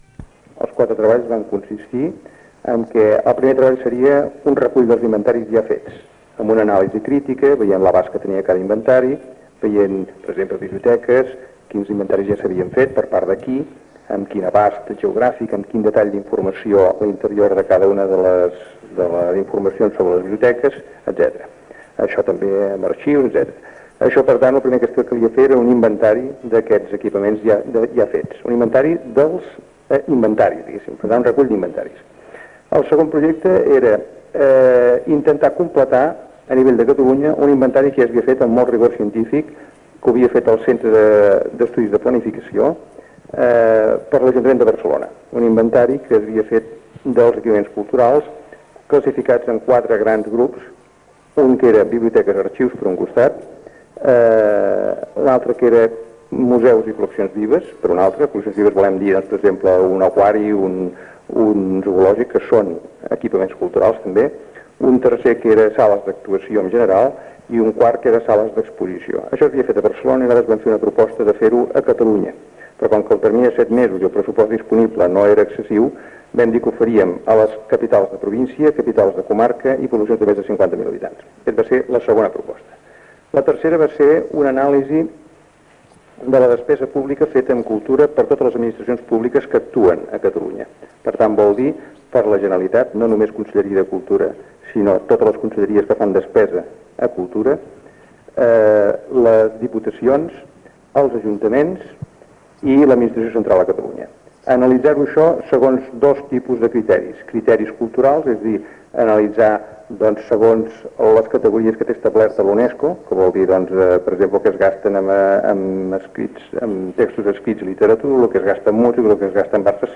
Tema musical.